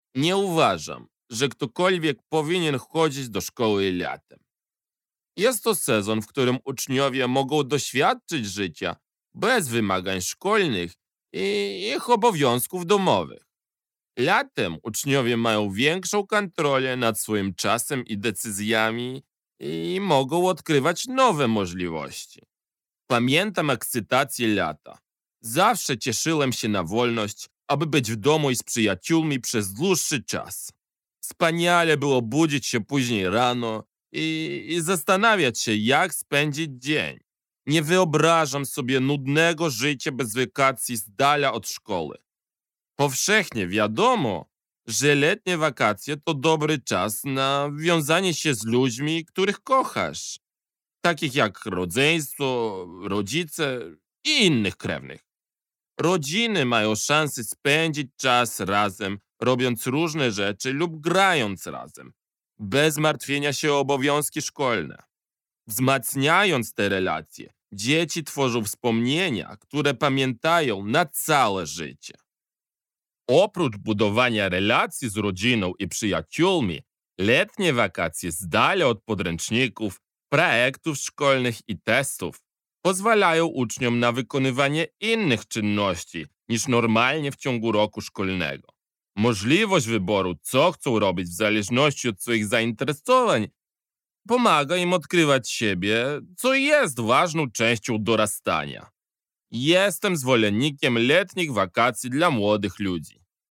[Note: In the transcript below, two dots indicate that the speaker paused. The three-dot ellipsis indicates that the speaker omitted text when quoting from the article. Errors in emphasis are indicated in brackets.]